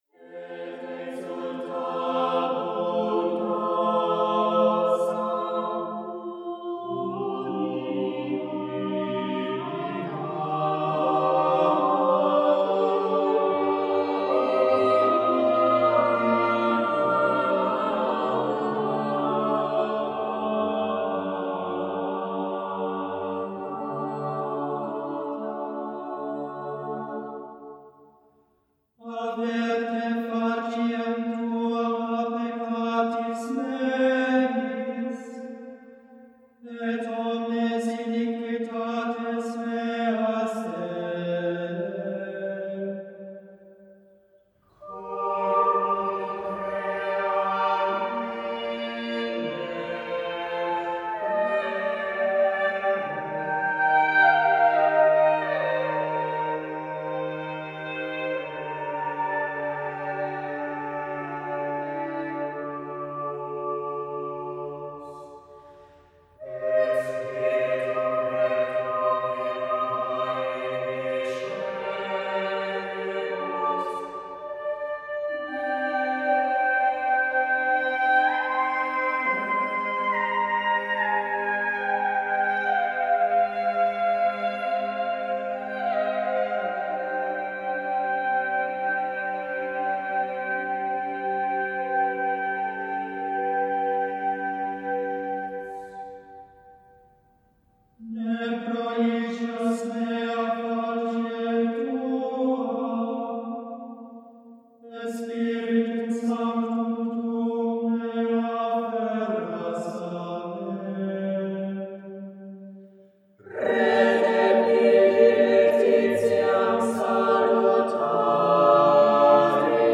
Choral